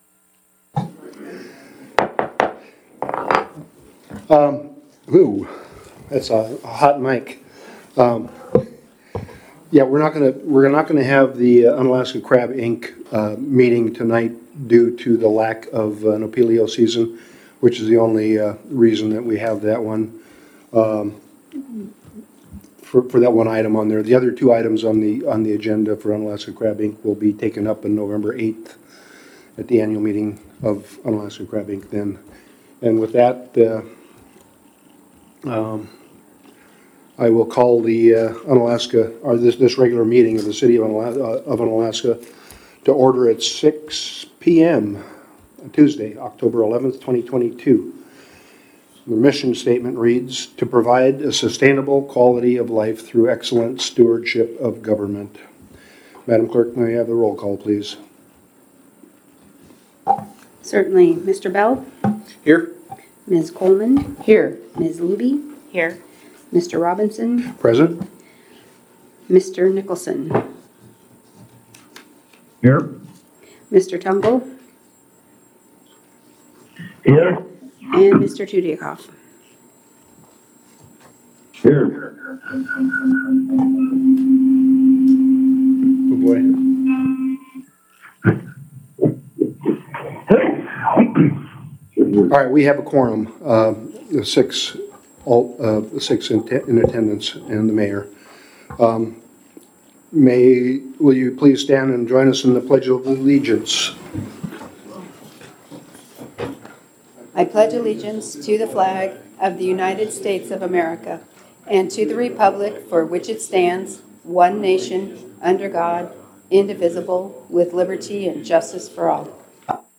City Council Meeting - October 11, 2022 | City of Unalaska - International Port of Dutch Harbor
In person at City Hall (43 Raven Way)